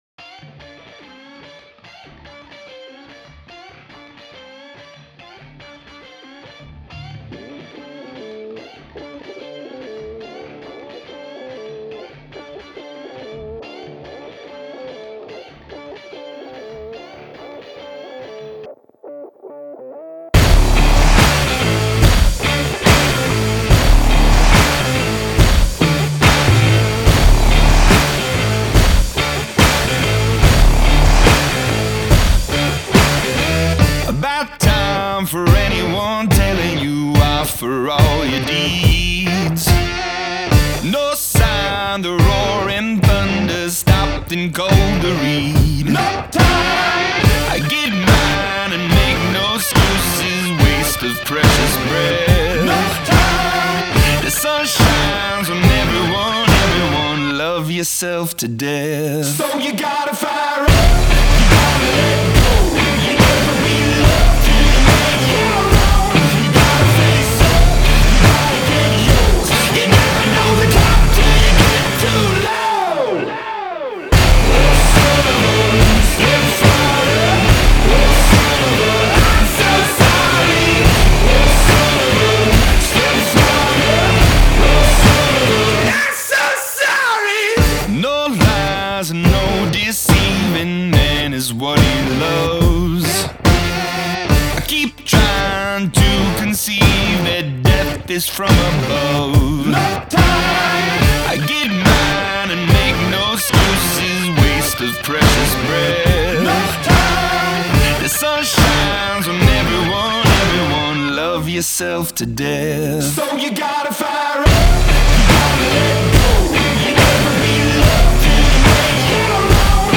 Genres: Rock, Pop Rock, Alternative Release Date